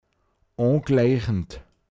pinzgauer mundart
o(n)gläichnt angesiedelt sein